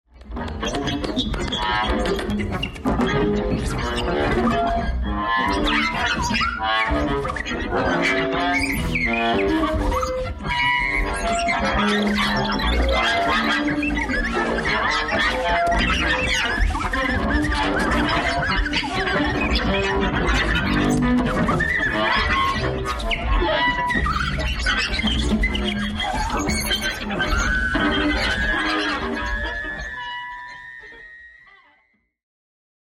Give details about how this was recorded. at morphine raum, berlin